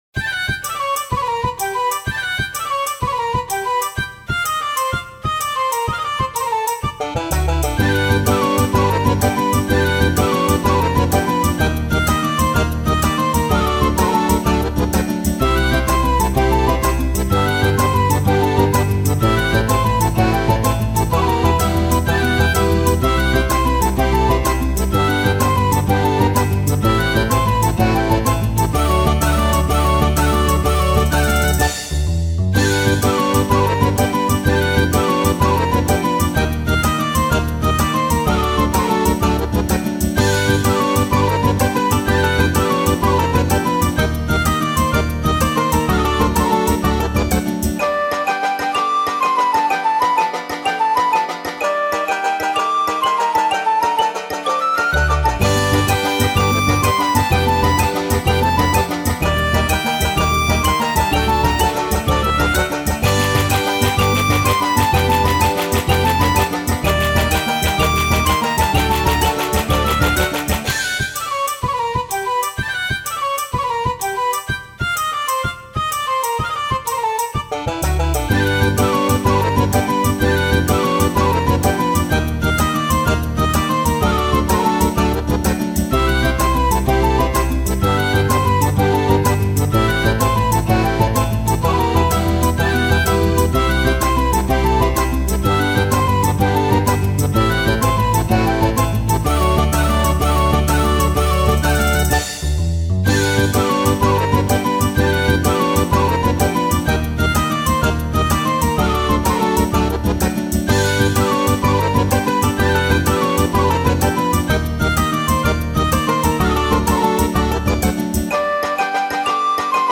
ogg(L) 村 陽気 北欧風 リズミカル
笛と弦の軽やかな旋律が北欧音楽の香りを漂わせる。